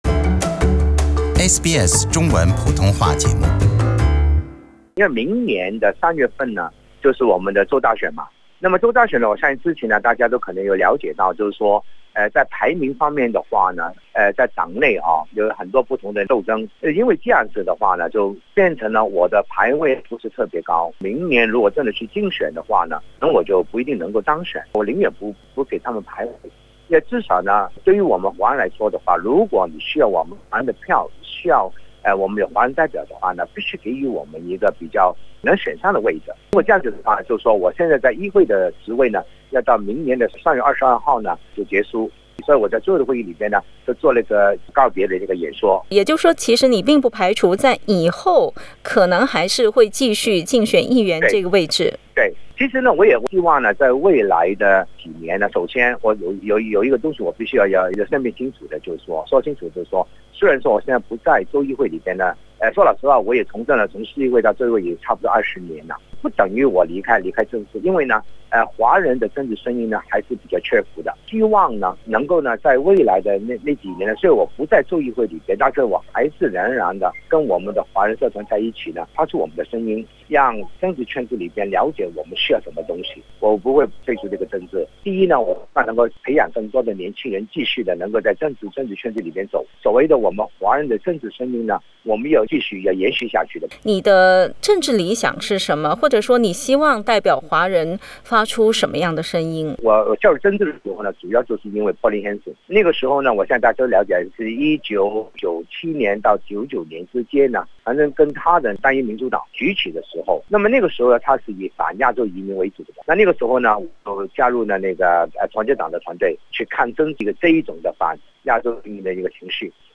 工党议员王国忠近日在上议院发表告别演说，但是在随后接受SBS普通话节目采访时，王国忠强调，这并不意味着他将退出政坛。
点击收听详细的采访报道。